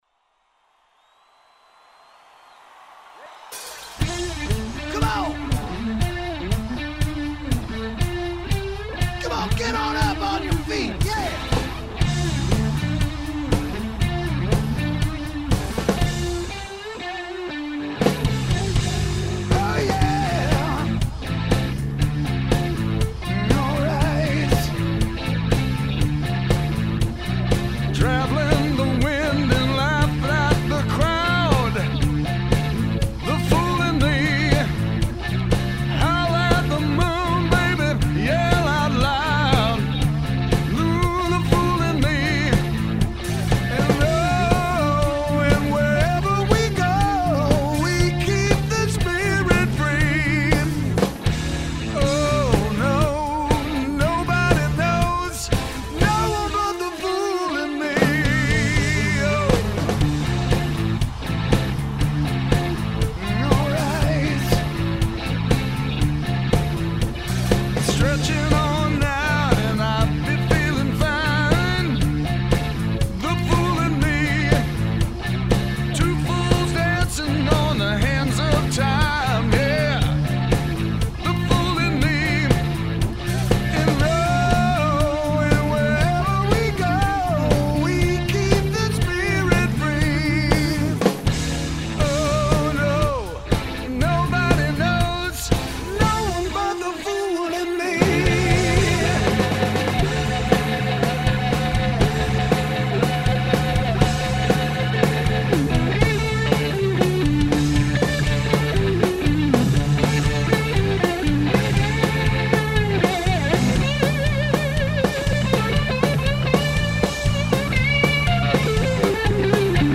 Live Studio Mix